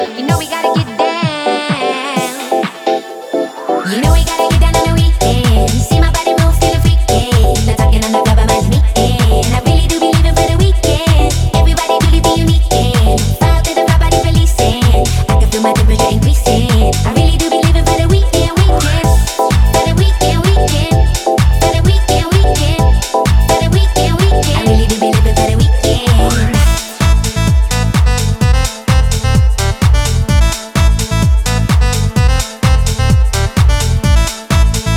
Dance House
Жанр: Танцевальные / Хаус